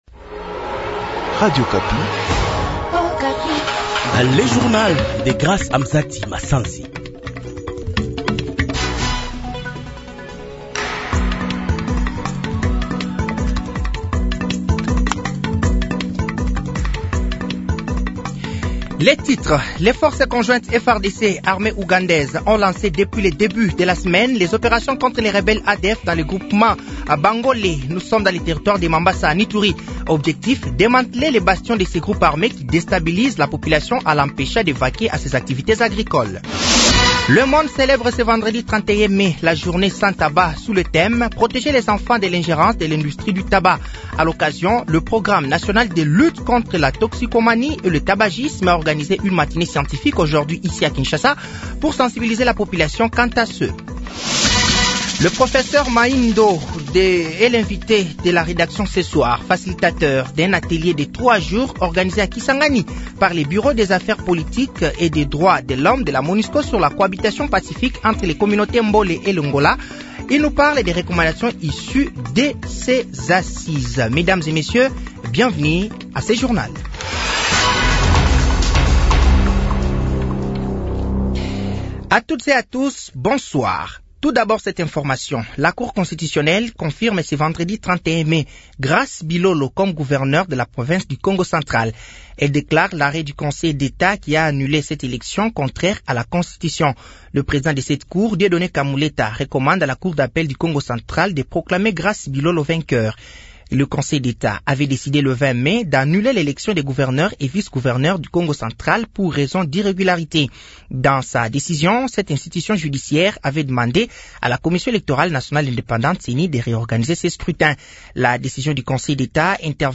Journal français de 18h de ce vendredi 31 mai 2024